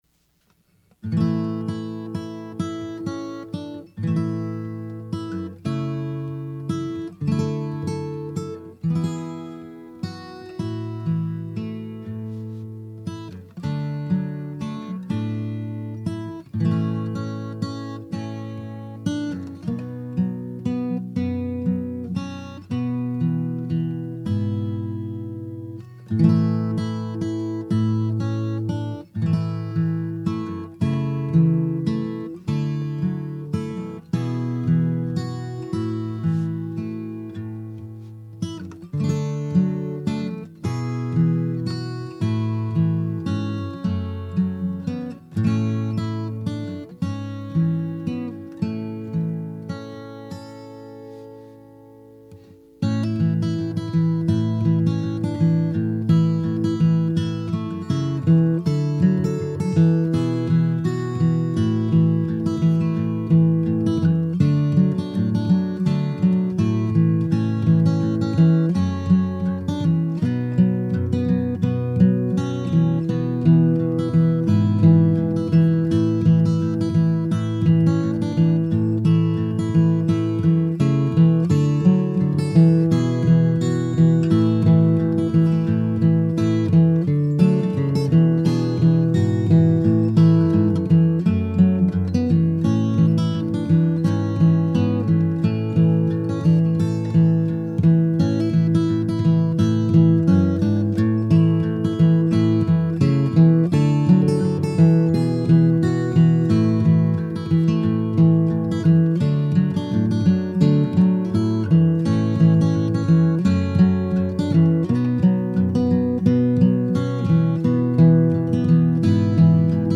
One source believes the melody to be “an old French Folk tune.”
I’ve long enjoyed this song, both the words and its jaunty, infectious melody.
For your Thanksgiving listening pleasure, I have arranged and recorded a fingerstyle, acoustic guitar arrangement of this timeless song.